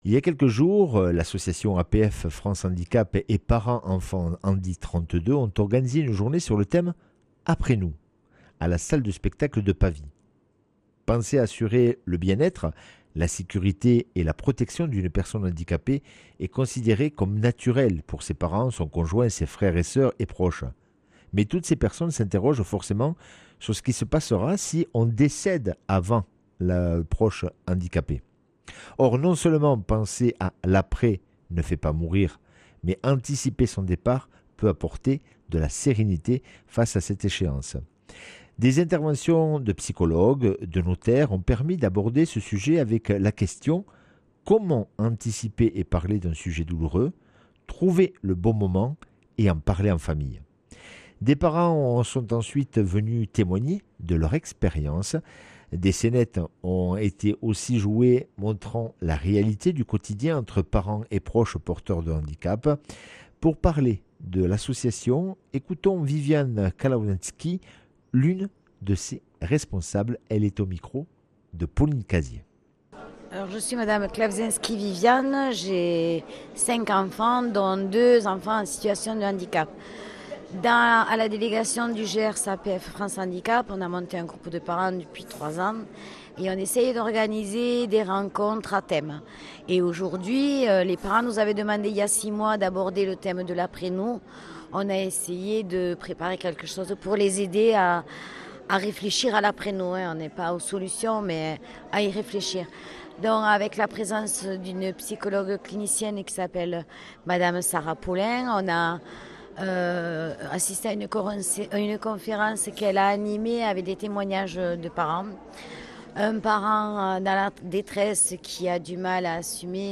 mercredi 18 juin 2025 Interview et reportage Durée 10 min